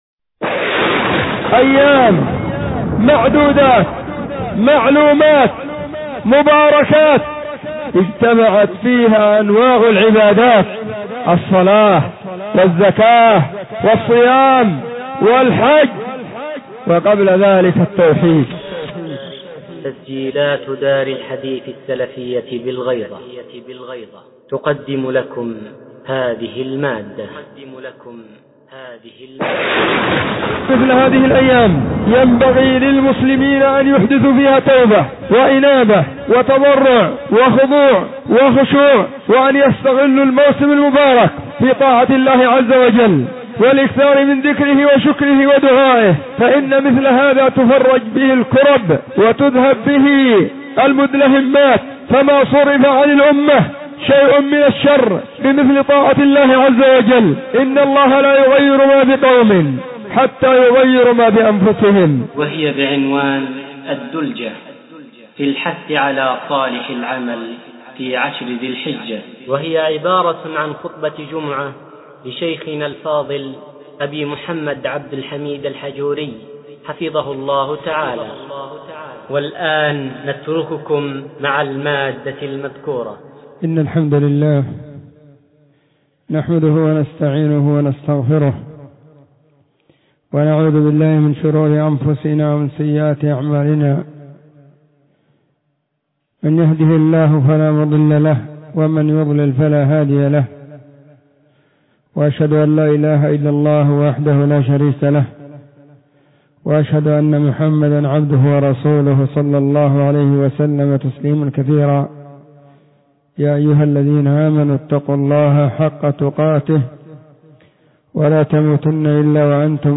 📢 وكانت في مسجد الصحابة بالغيضة محافظة المهرة – اليمن.